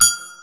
JJPercussion (390).wav